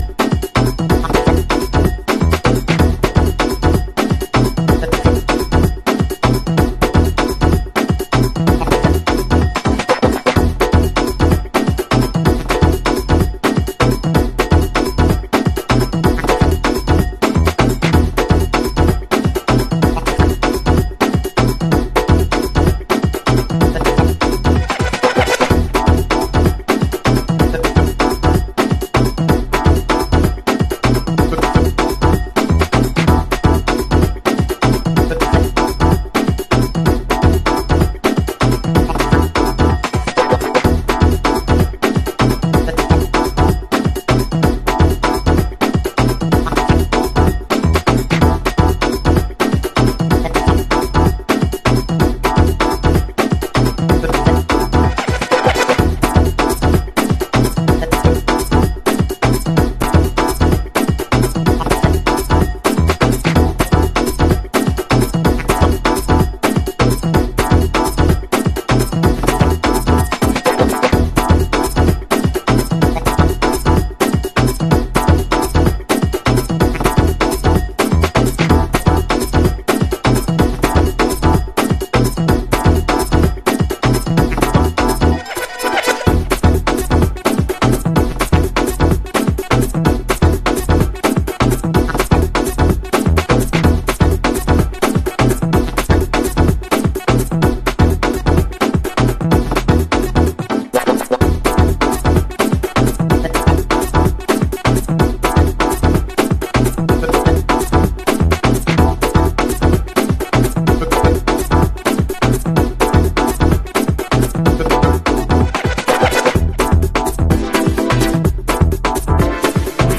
House / Techno
このエレクトロとテクノを折衷したグルーヴはハマります。